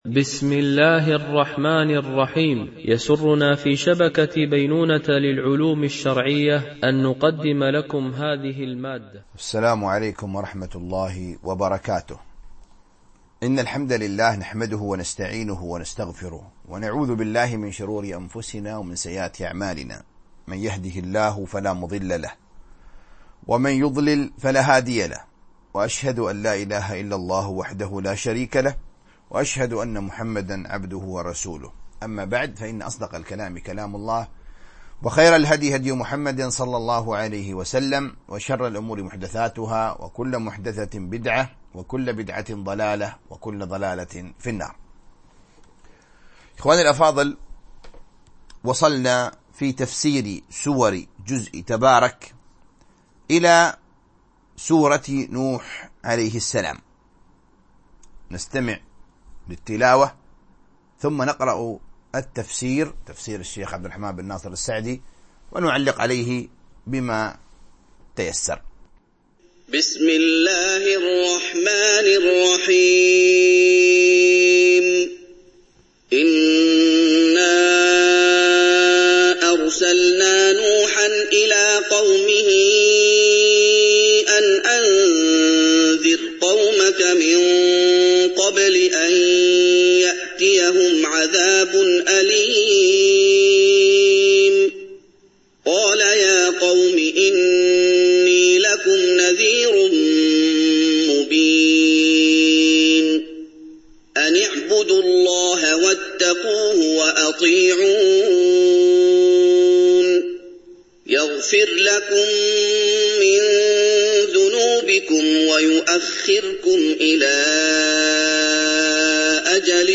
القسم: التفسير